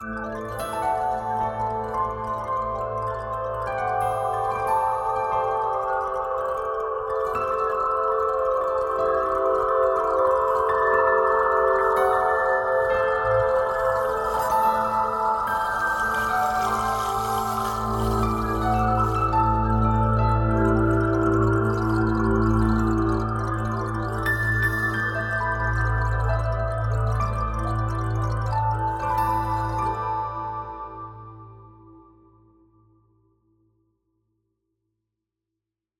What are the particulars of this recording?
Sonification is a process that translates data from telescopes into sounds.